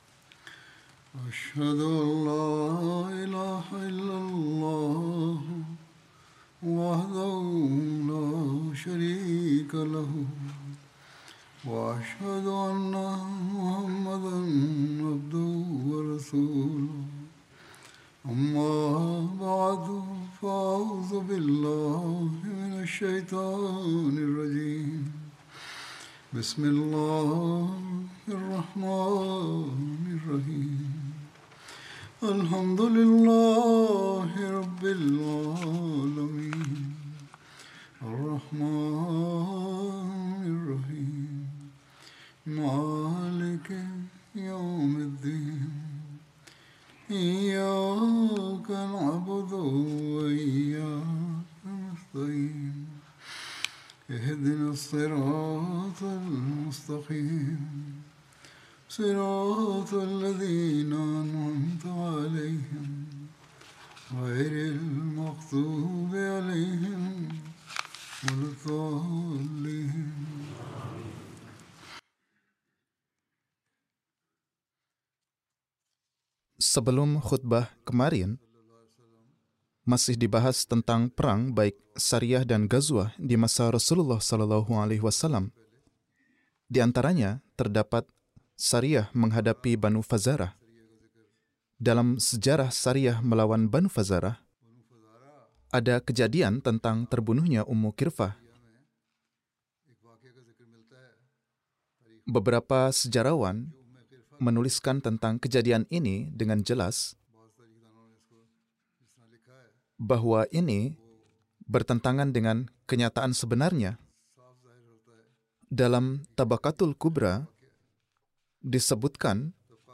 Indonesian translation of Friday Sermon